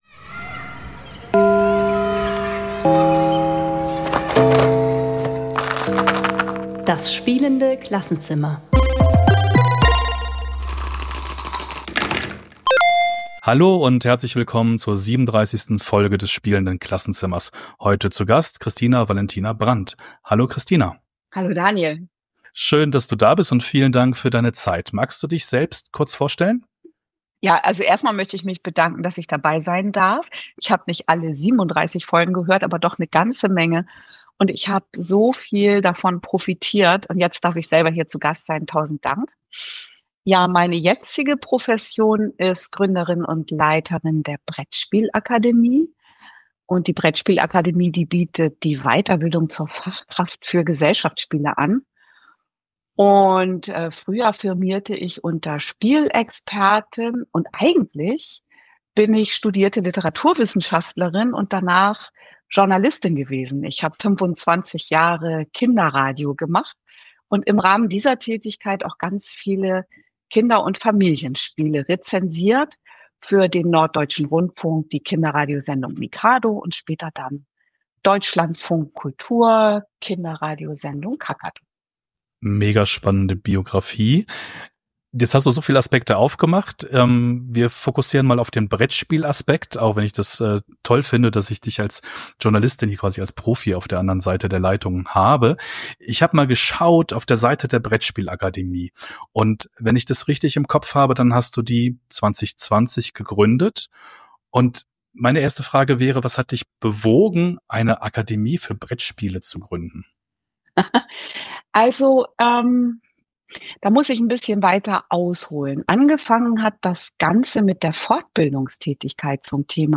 Brettspielbildung - Bildung ist mehr als Wissen (Interview